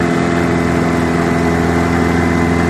20 hp Johnson Boat Drive Loop Driving, On Board